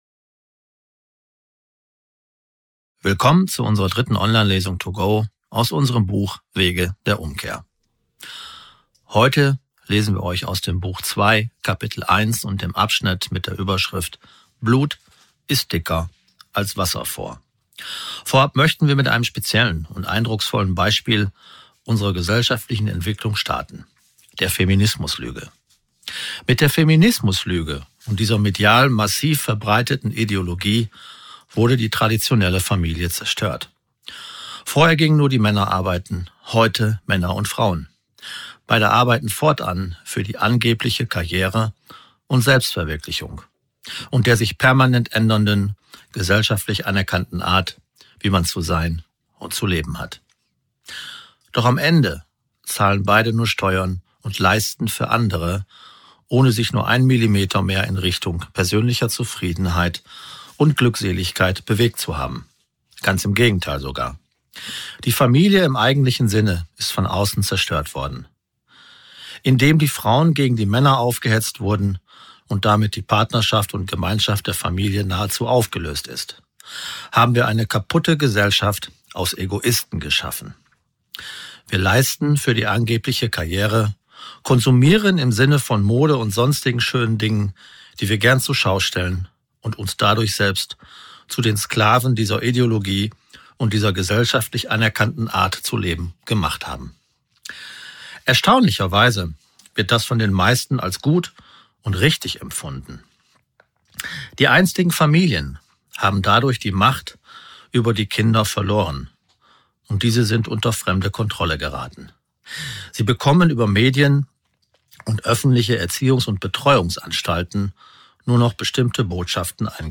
Lesung to Go - Institut EKGS
Bequeme Online-Lesungen mit Appetitanregern in gut verdaulichen „Portionen“.